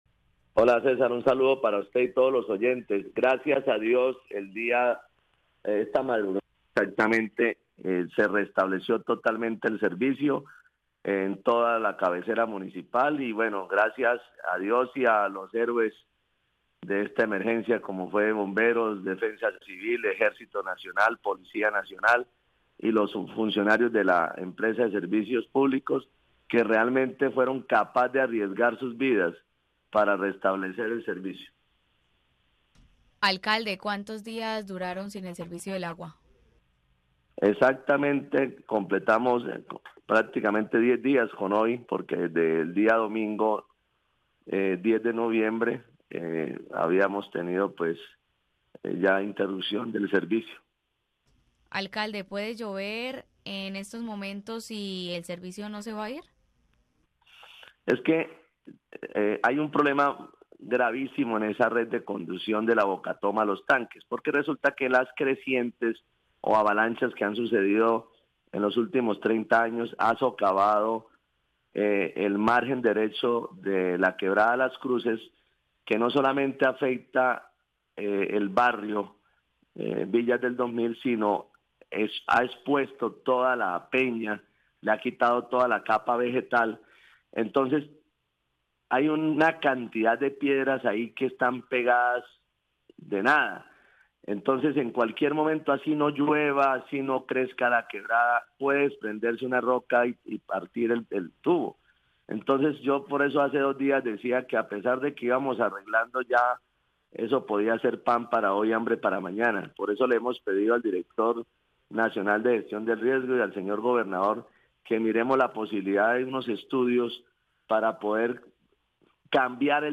Oscar Sanmiguel, alcalde de San Vicente de Chucurí